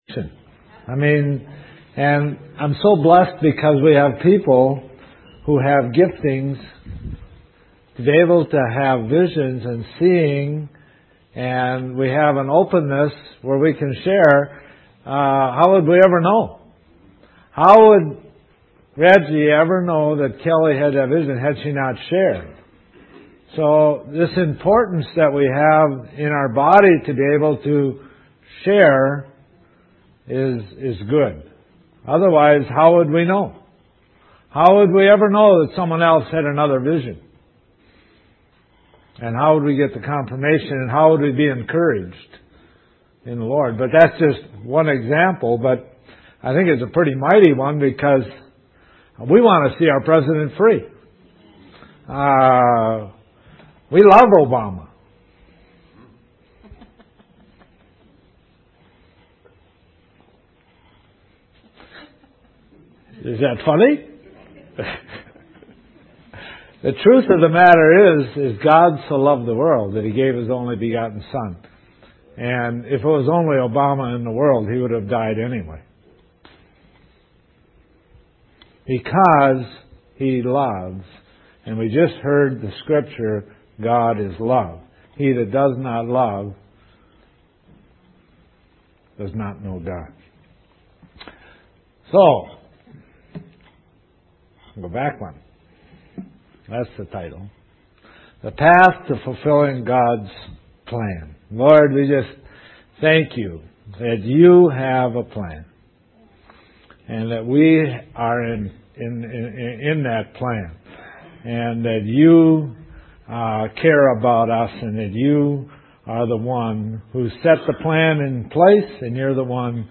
This entry was posted in sermons .